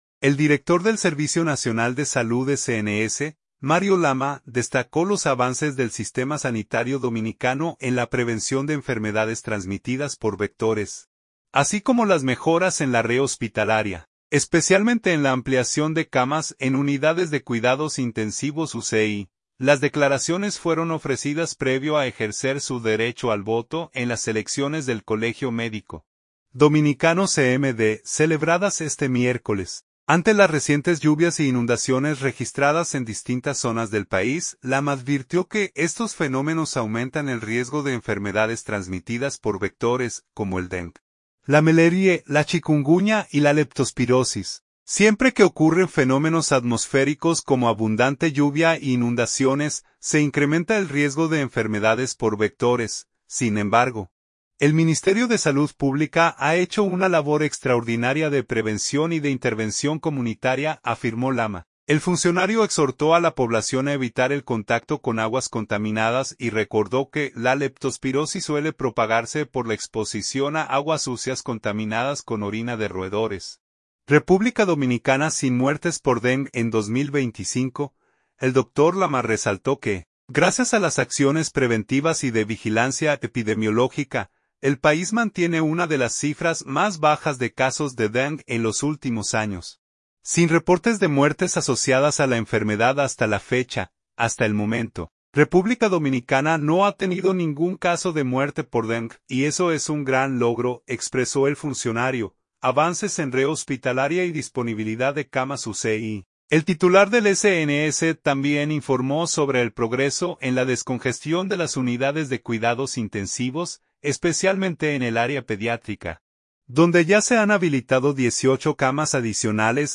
Las declaraciones fueron ofrecidas previo a ejercer su derecho al voto en las elecciones del Colegio Médico Dominicano (CMD), celebradas este miércoles.